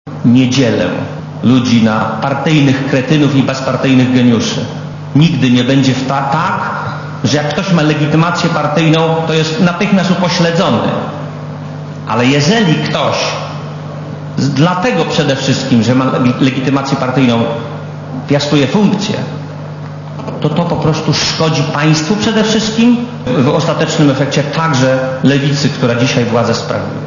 Oprócz bicia się w pierś, politycy SLD wysłuchali też premiera Marka Belki, którego rząd niedawno poprali.